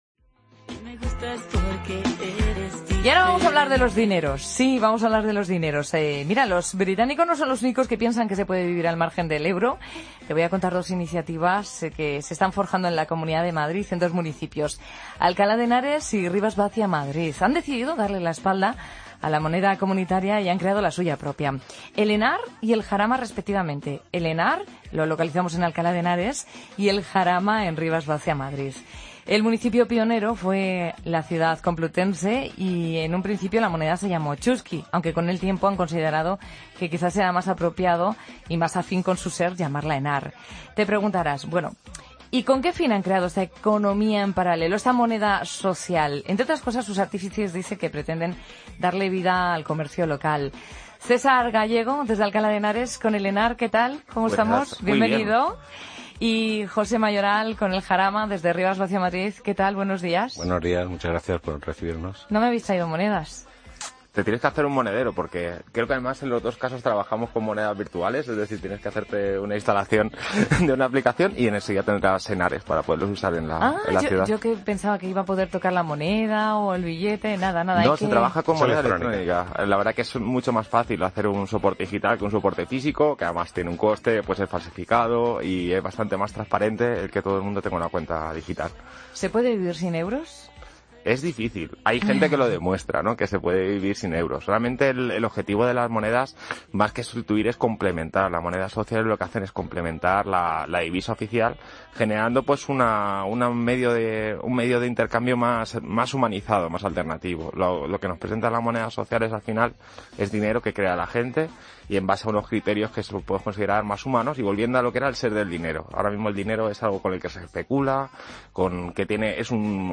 Entrevista a los promotores de la moneda social de Alcalá de Henares y Rivas-Vaciamadrid